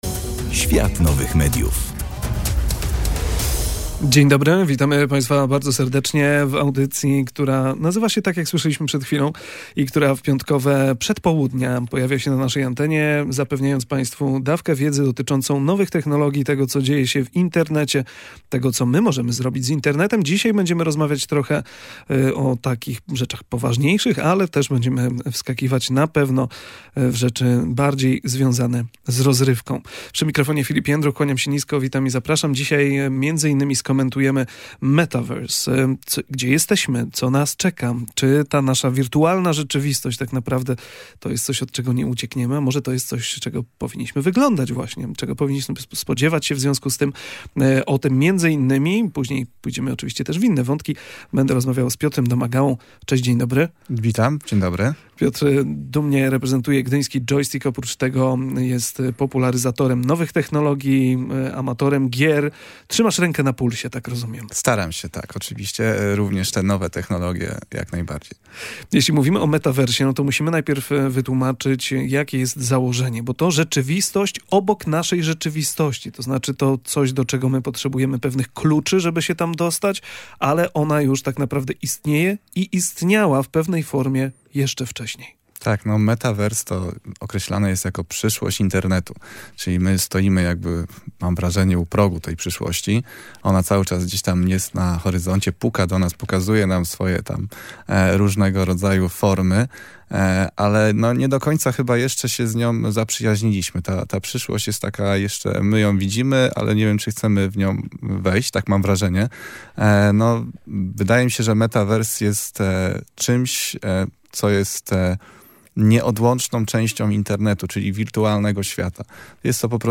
W kolejnej audycji „Świat Nowych Mediów” rozmawialiśmy o roli Metaverse w przyszłości internetu, technologicznych gadżetach, które nie odniosły sukcesu, oraz e-sporcie, jako dynamicznie rozwijającej się gałęzi rozrywki.